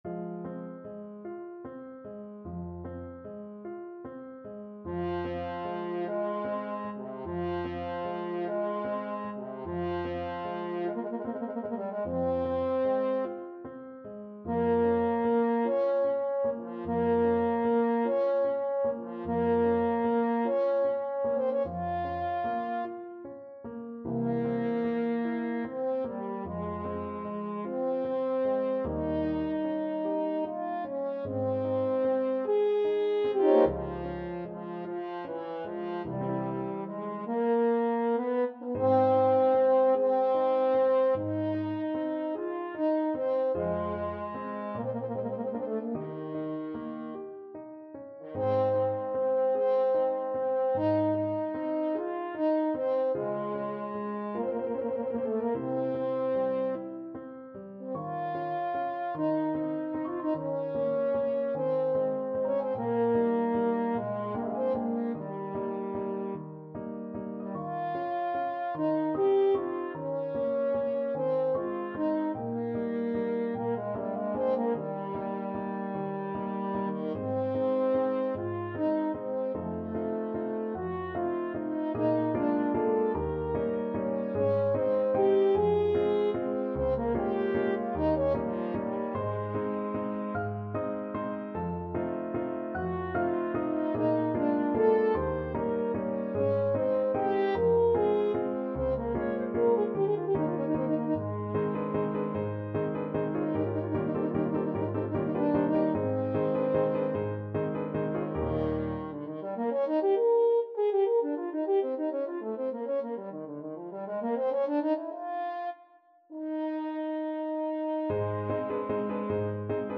Classical Verdi, Giuseppe D'amor sull'ali rosee from Il Trovatore French Horn version
~ = 100 Adagio =50
F minor (Sounding Pitch) C minor (French Horn in F) (View more F minor Music for French Horn )
4/4 (View more 4/4 Music)
C4-Db6
French Horn  (View more Intermediate French Horn Music)
Classical (View more Classical French Horn Music)